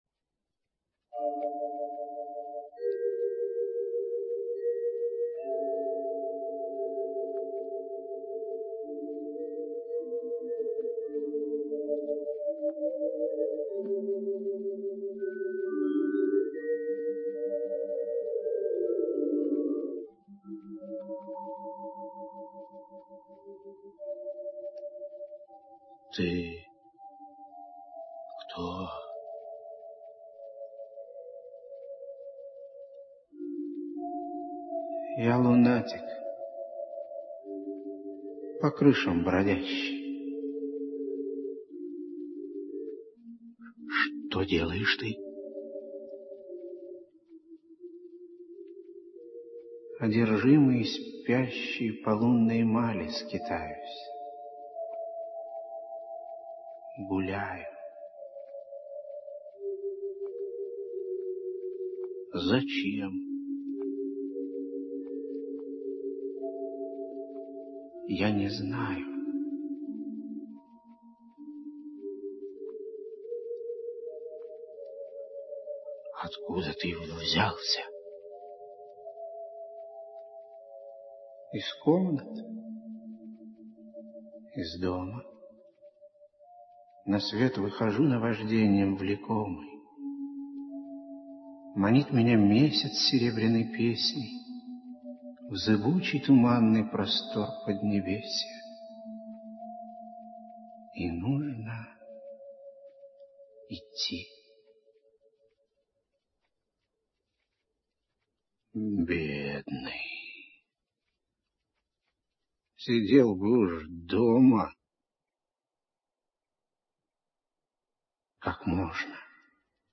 Послушайте, как оно звучит в исполнении Анатолия Шагиняна (фрагмент музыкальной композиции «Песни людские»):